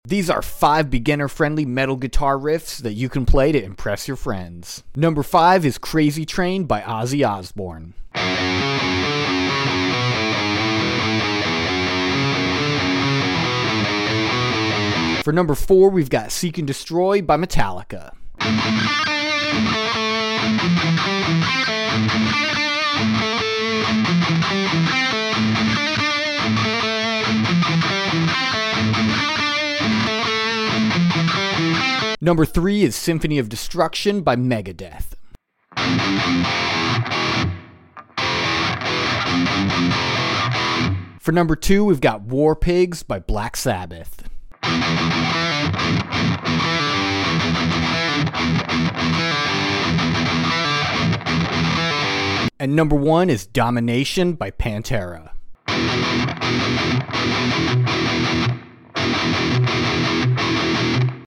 5 EASY Metal Guitar Riffs sound effects free download